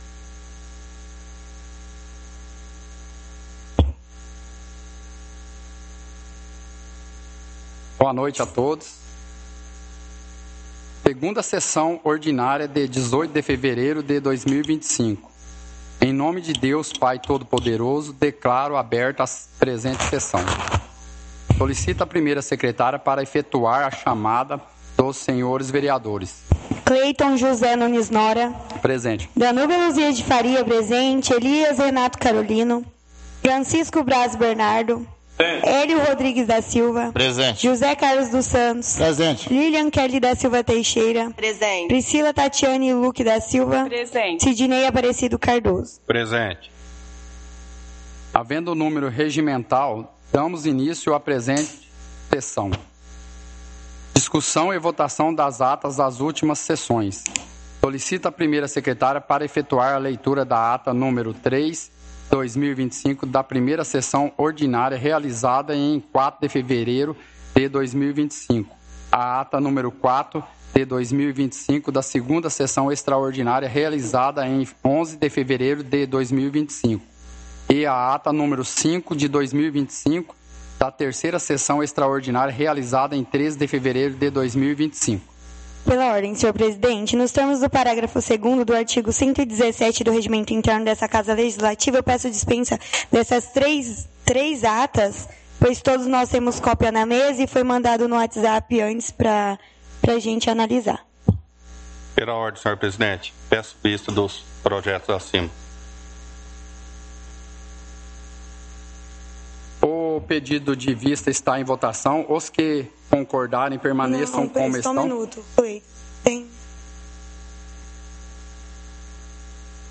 Áudio da 2ª Sessão Ordinária – 18/02/2025